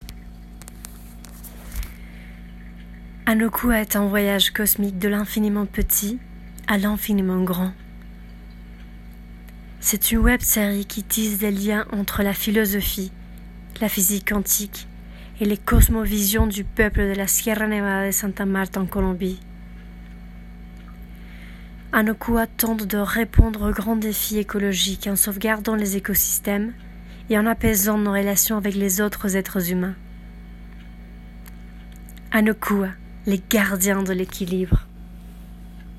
Voix OFF - Français - Anokua Français 2
25 - 50 ans - Contralto